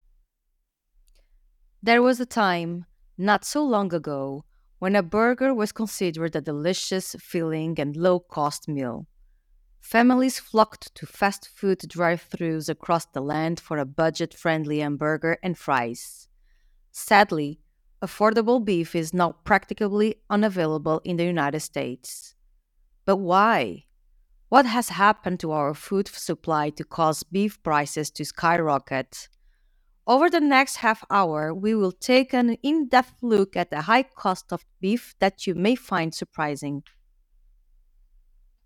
DEMO - Beef VO.mp3
English - USA and Canada
Young Adult